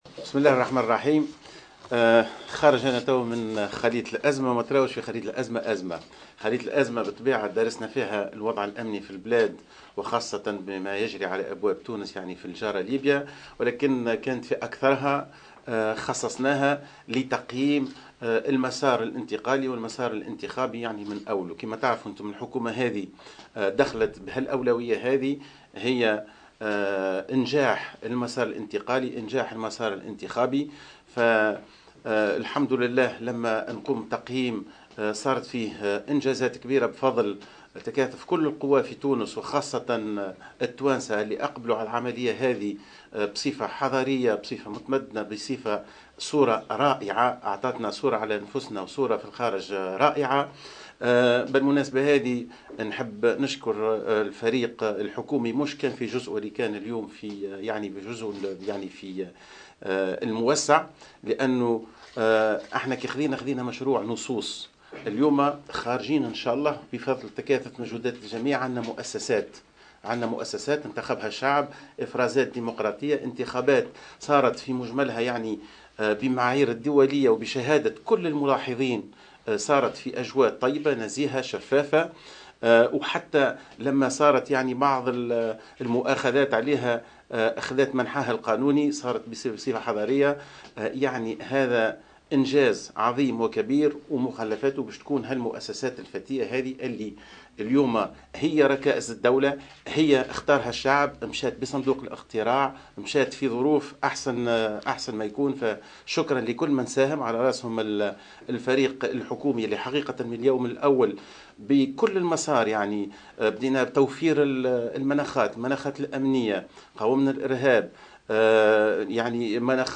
Dans une déclaration accordée à Jawhara FM, le chef de gouvernement, Mahdi Jomaa, a précisé qu’il a contacté Béji Caïed Essebsi et Mohamed Moncef Marzouki qui ont affirmé leur engagement à protéger l’union nationale et les établissements de l’Etat.